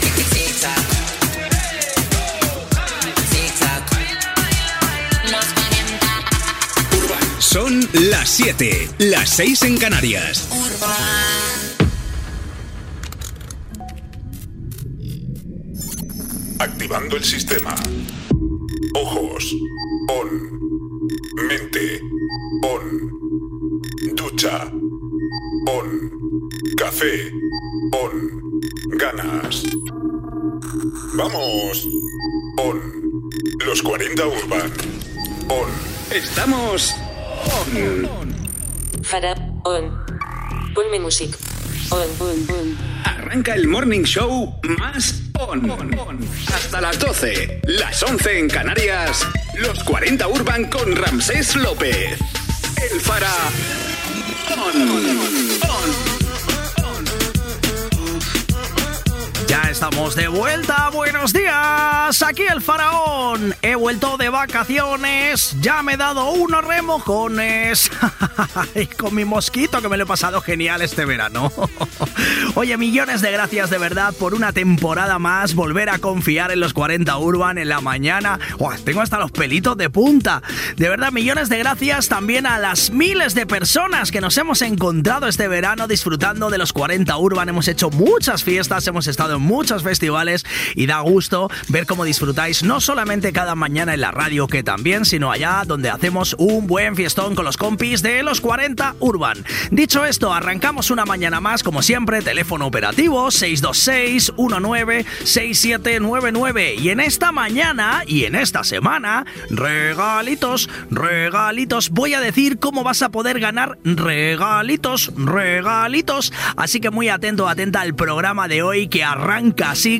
Hora, careta del programa, presentació en l'inici de la temporada 2025-2026. Formes de participar, data, indicatiu,
Musical
FM